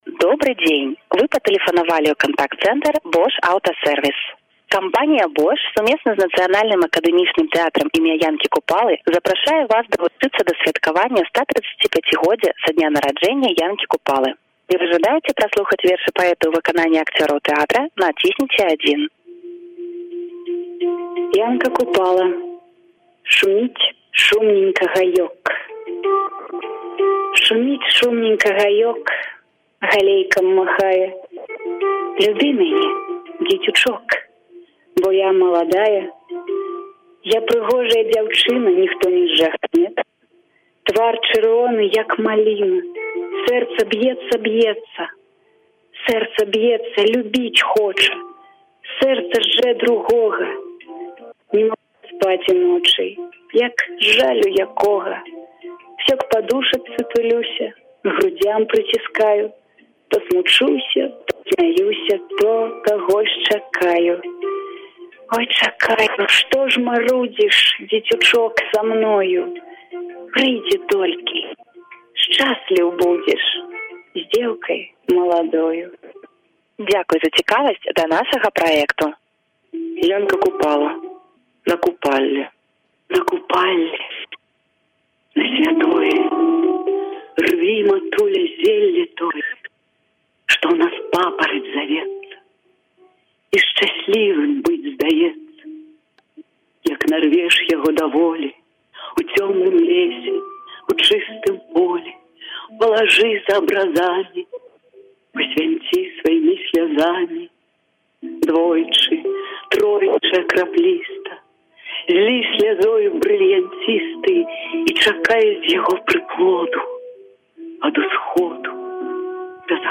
Вершы Янкі Купалы загучалі ў тэлефонах кліентаў Bosch. (Запіс з тэлефону)